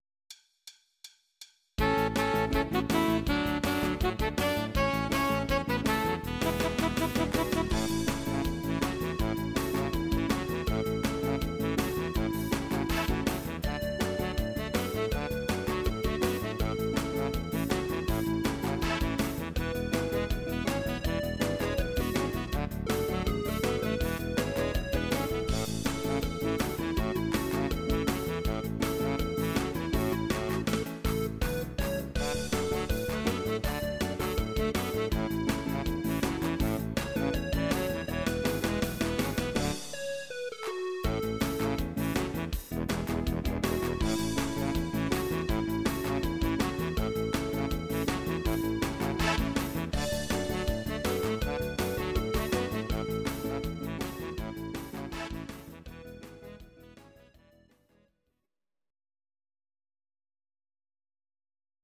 Audio Recordings based on Midi-files
Pop, Oldies, Irish Music, 1960s